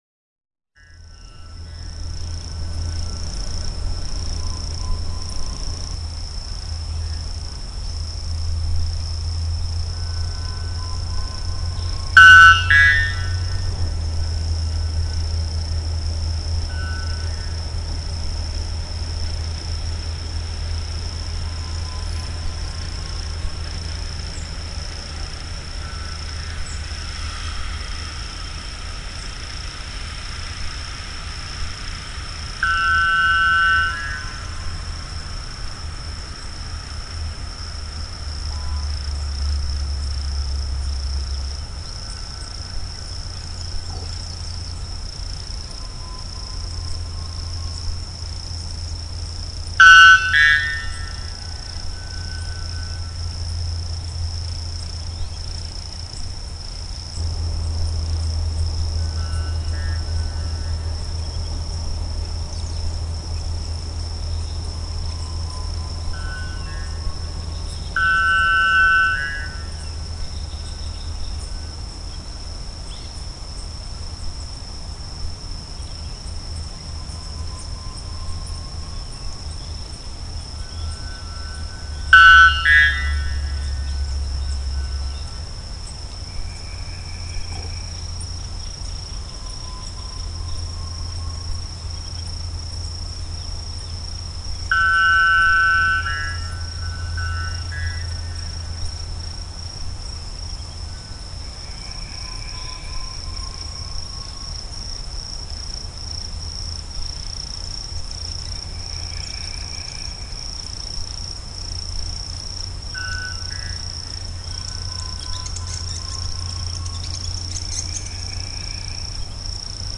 Geräuschdokumente der Natur.
Aufnahmen von Orten, die wir auf unseren Exkursionen besuchen :
Glockenvogel (Procnias averano)